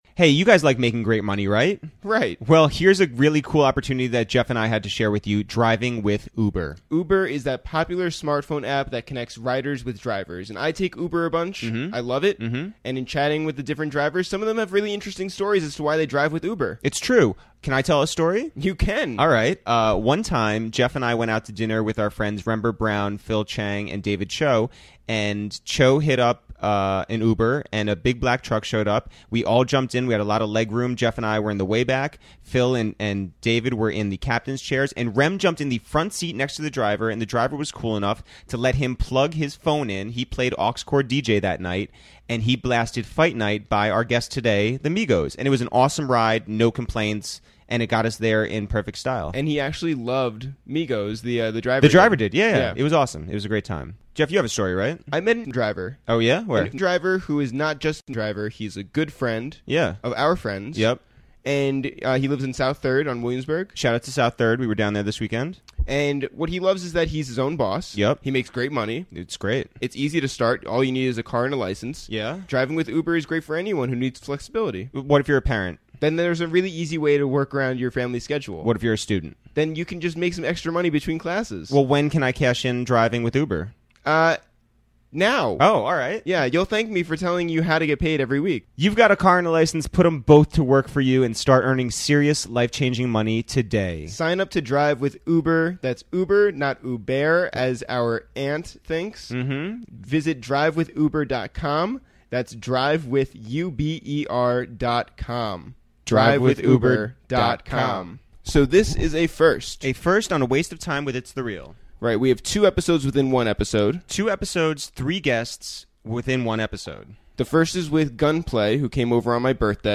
He talks about the Meek Mill and Drake situation, his extremely thorough drug use, and most importantly: the fact that he has more teeth than the average human. We also welcomed the Migos to the Upper West Side this week, and spoke with them about how Gucci puts out so much music, traveling through Russia, and of course, Quavo and Takeoff spit their favorite ad-libs.